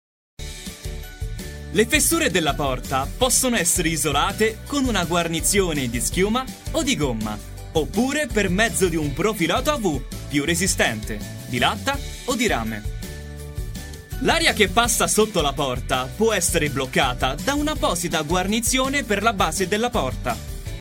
意大利语翻译团队成员主要由中国籍和意大利籍的中意母语译员组成，可以提供证件类翻译（例如，驾照翻译、出生证翻译、房产证翻译，学位证翻译，毕业证翻译、成绩单翻译、无犯罪记录翻译、营业执照翻译、结婚证翻译、离婚证翻译、户口本翻译、奖状翻译等）、公证书翻译、病历翻译、意语视频翻译（听译）、意语语音文件翻译（听译）、技术文件翻译、工程文件翻译、合同翻译、审计报告翻译等；意大利语配音团队由意大利籍的意大利语母语配音员组成，可以提供意大利语专题配音、意大利语广告配音、意大利语教材配音、意大利语电子读物配音、意大利语产品资料配音、意大利语宣传片配音、意大利语彩铃配音等。
意大利语样音试听下载